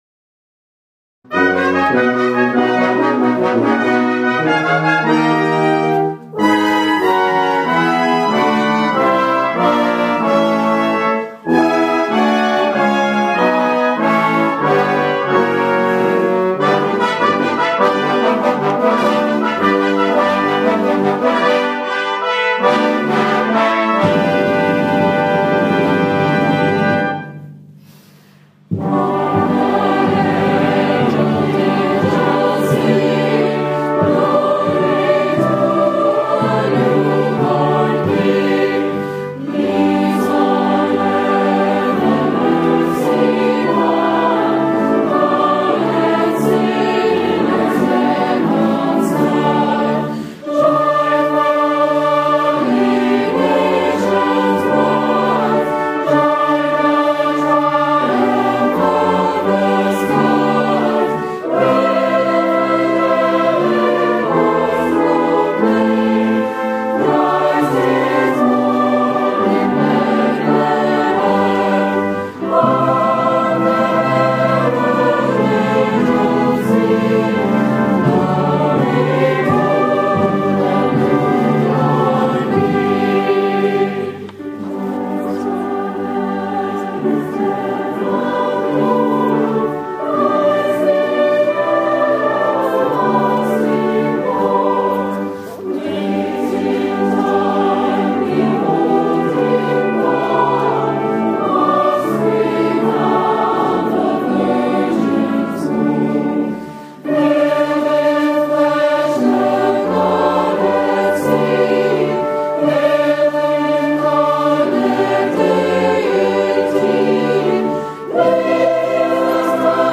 Mardi soir, ma chorale est allée (en bus scolaire) sur une base militaire (dont j’ignorais l’existence) au milieu de nulle part, à une trentaine de kilomètres d’ATPN, pour aller chanter avec The Royal Canadian Artillery Band, les musiciens de l’Artillerie royale canadienne!
Vous entendrez aussi que je n’ai jamais chanté la plupart de ces chants donc c’est parfois très moche et je m’en excuse! Heureusement, avec un orchestre composé majoritairement de cuivres et de percussions, on peut chanter n’importe comment et ça passe très bien!
oh, le premier extrait m’a fait plonger dans Noël tout de suite ; quant à l’accent, ma voisine est originaire de Gaspésie et elle a cet accent dont je me régale… même après 15 ans de vie en France, elle l’a conservé, j’adore trop…